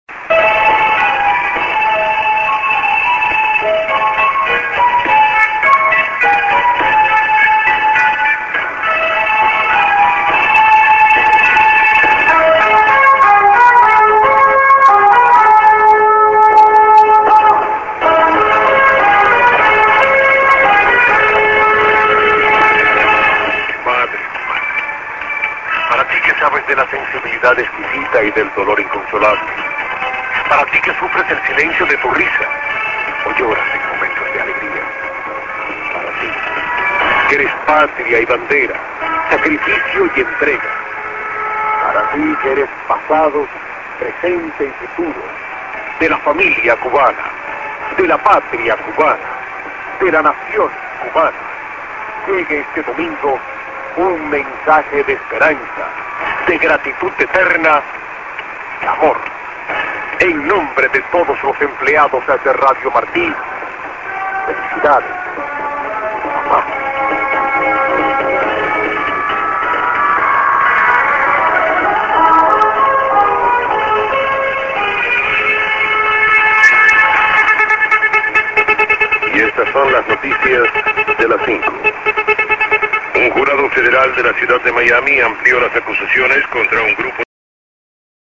St. IS->ID(man)->ST->